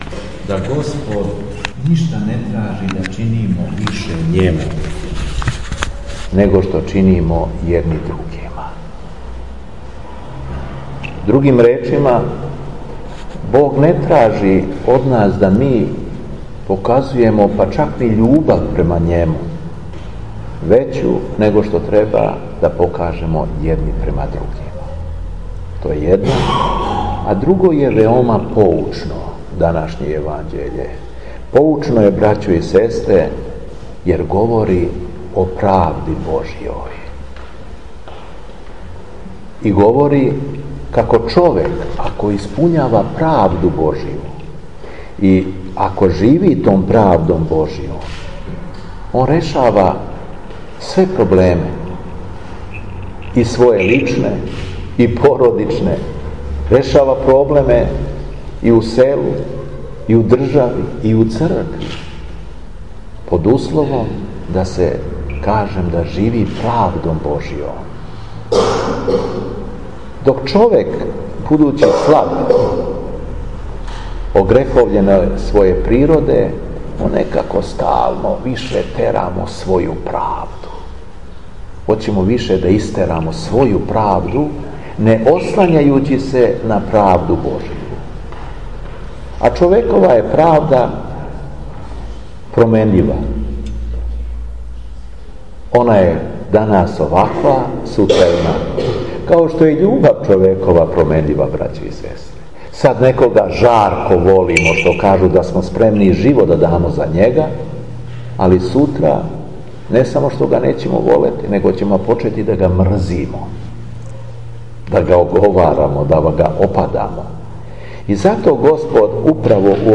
Његово Преосвештентсво Епископ шумадијски Господин Јован служио је Свету Архијерејску Литургију у храму светог Јоаникија Девичког у Бресници у среду,...
Беседа Епископа шумадијског Г. Јована